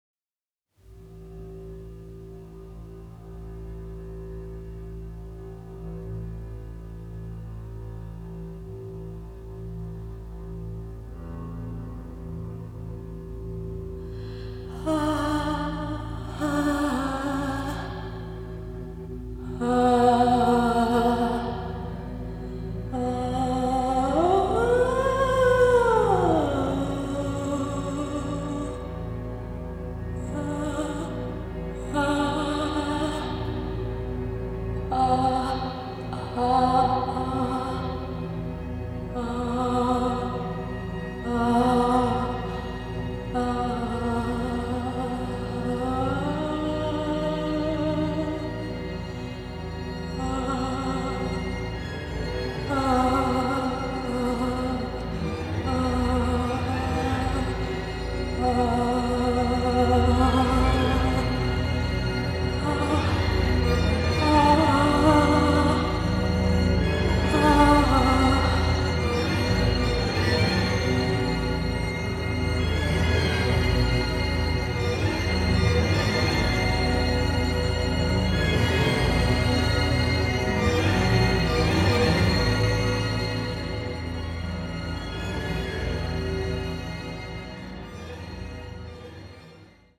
avantgarde score